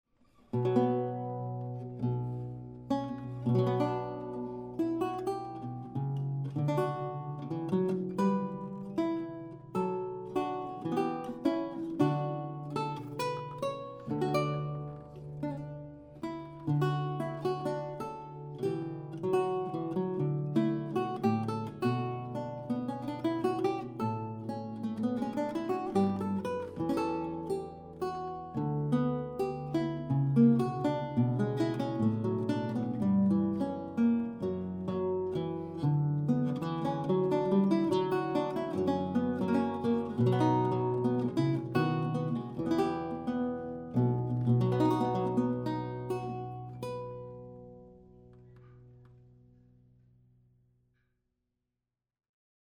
Auf Anfrage vom Tontechnikerforum 3db hier ein paar Samples, die die anerkannte Kombination CMC5 + MK2s von Schoeps mit dem vergleichsweise sehr günstigen Oktava MC012 mit Kugelkapsel vergleichen.
RME Fireface 800, Wohnzimmer, Abstand: ca. 0,5m, AB-Stereo mit Basis 30cm, keine Effekte oder EQ.
Prélude Schoeps MK2s
PreludeSchoepsMK2s.mp3